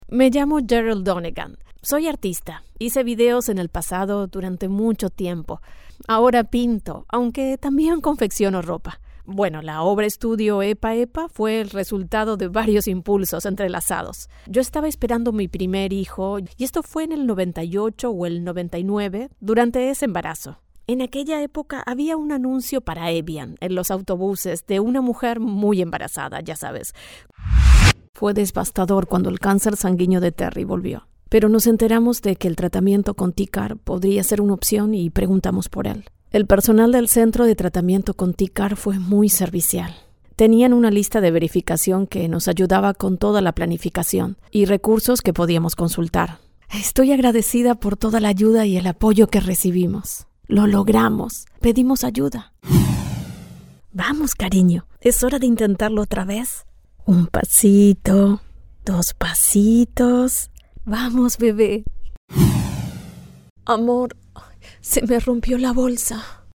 Spaans (Latijns Amerikaans)
Warm, Diep, Natuurlijk, Veelzijdig, Zakelijk, Jong, Stoer, Toegankelijk, Vriendelijk, Zacht